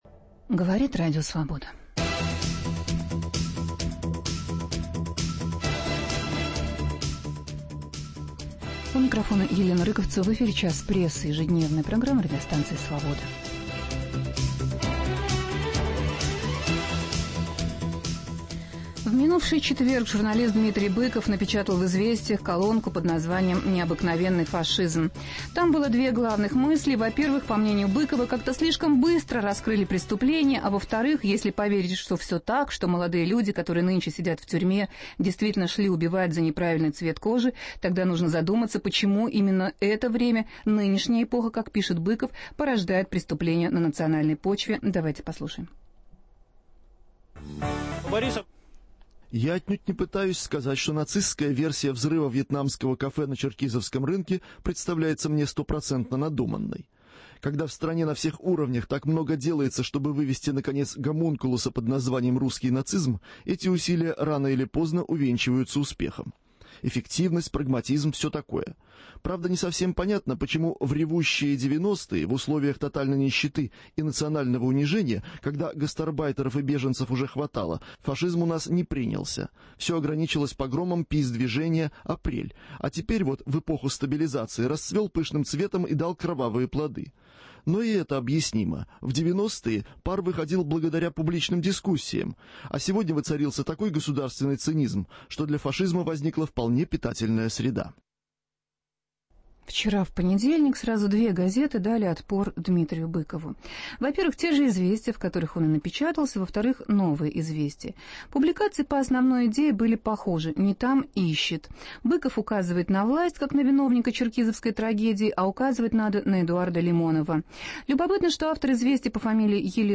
Программа "Час прессы" рассказывает, как эта кампания ведется сейчас в средствах массовой информации. В студии журналист Дмитрий Быков, статья которого "Необыкновенный фашизм" послужила одним из источников вдохновения для организаторов кампании.